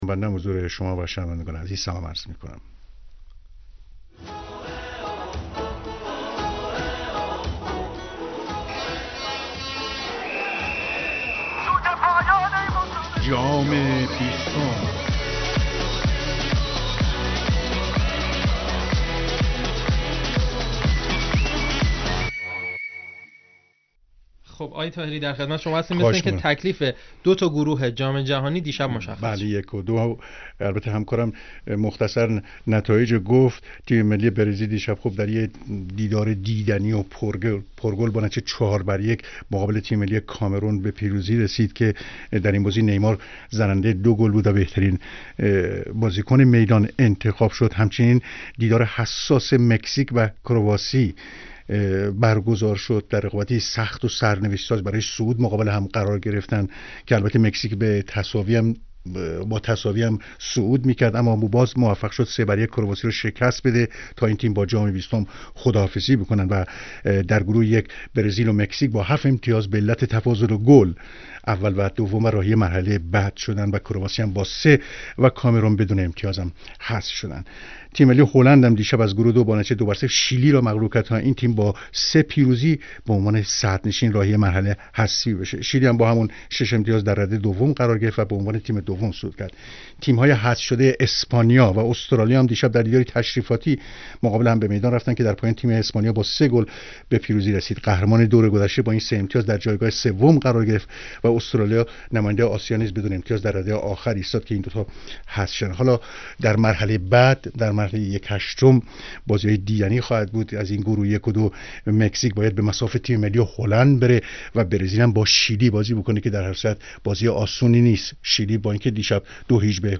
خبرگزاری تسنیم: گزارش صوتی از آخرین خبرها و حواشی جام جهانی فوتبال روز دوازدهم منتشر می شود.